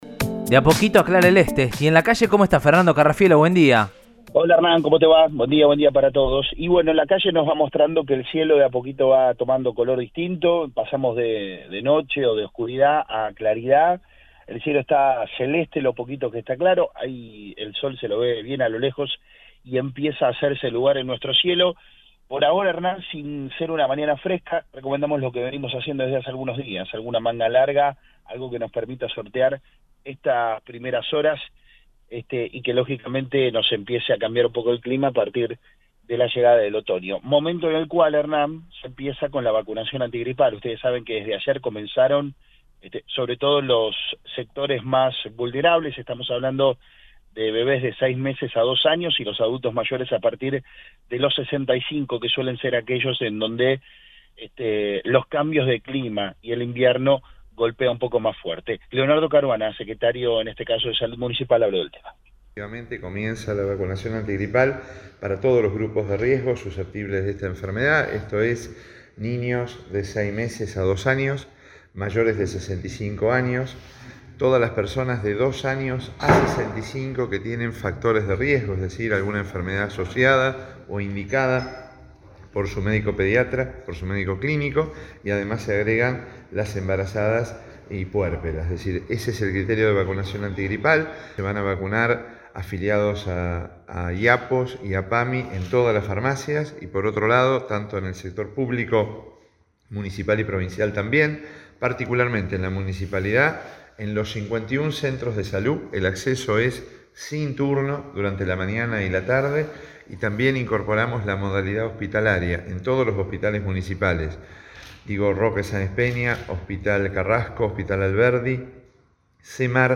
“Es para todos los grupos de riesgo: niños de 6 meses a 2 años, mayores de 64 años, personas de 2 a 65 años con factores de riesgo y embarazadas y puérperas”, precisó el secretario de Salud municipal Leonardo Caruana, en diálogo con el móvil de Cadena 3 Rosario, en Radioinforme 3.